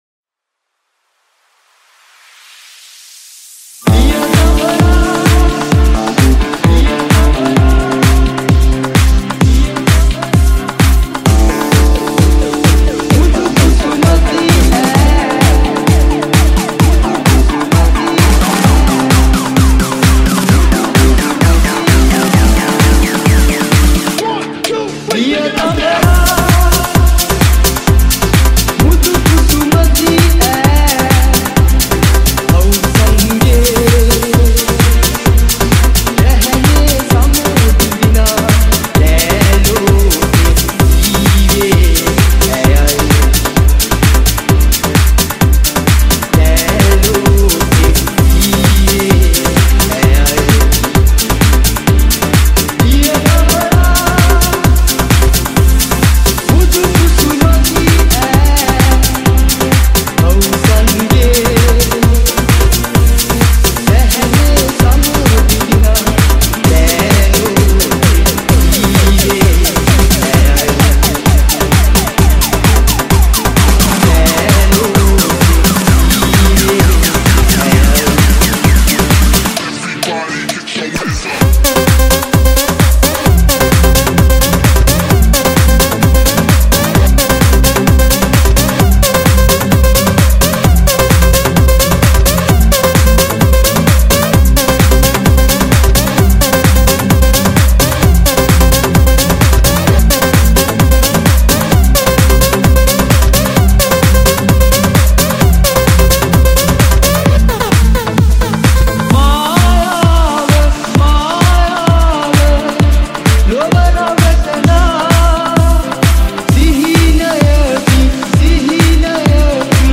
Sinhala Remix Song | Sinhala DJ Songs